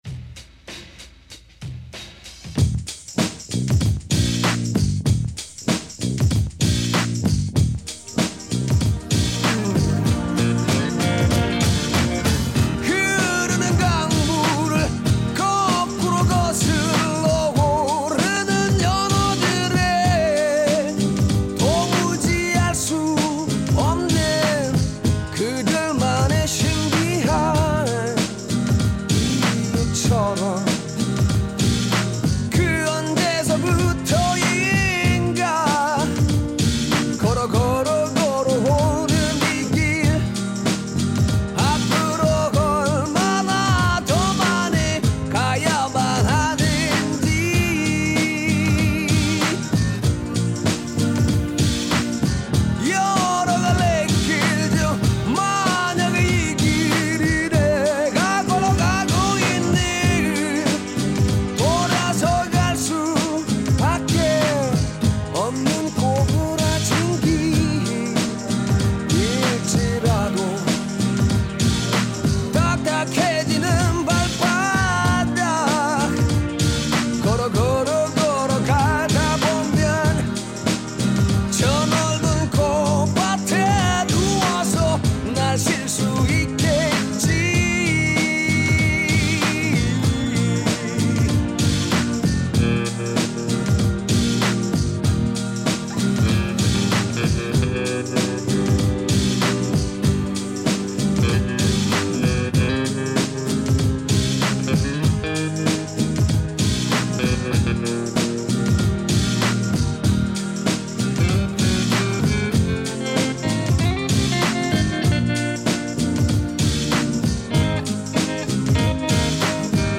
포크록 싱어송라이터